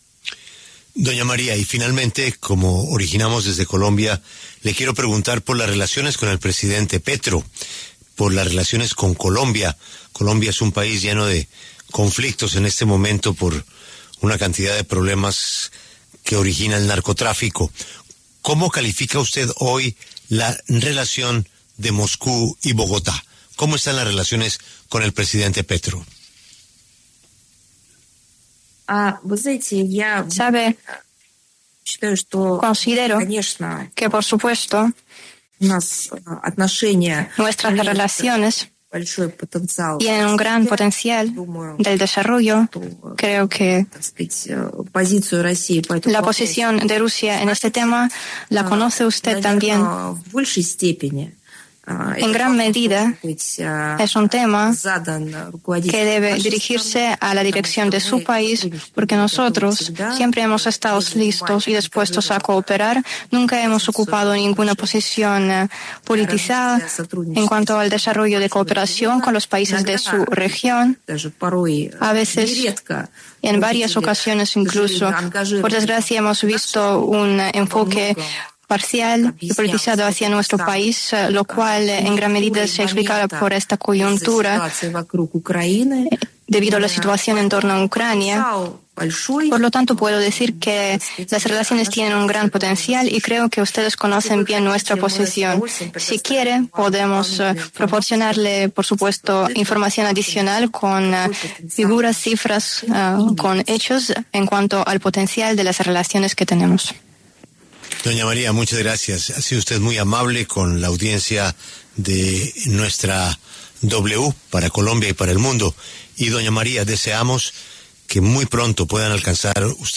María Zajárova, portavoz del Ministerio de Asuntos Exteriores de Rusia, pasó por los micrófonos de La W para hablar sobre diversos temas en torno a la guerra de su país con Ucrania.
Al final de la conversación, el director de La W, Julio Sánchez Cristo, le consultó a Zajárova sobre la relación del Gobierno de Vladimir Putin con el de Gustavo Petro.